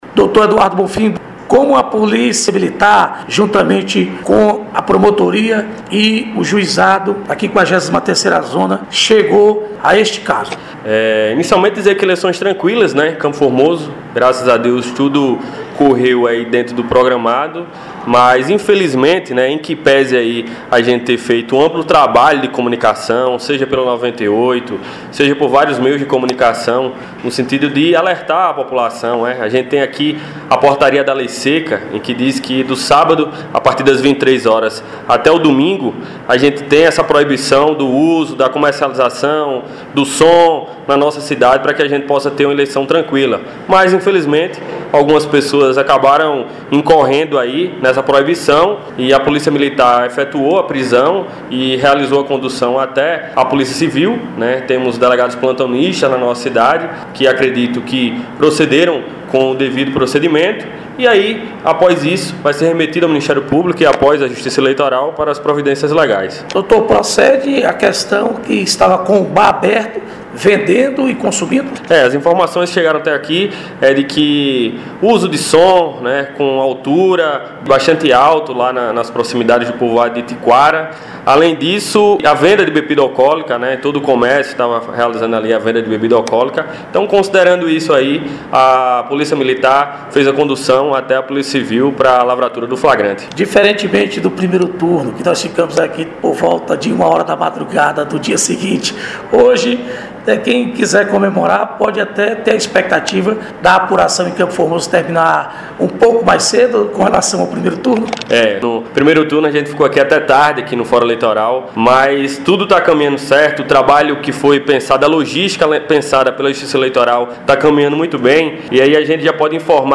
Dr. Eduardo Bonfim, juiz eleitoral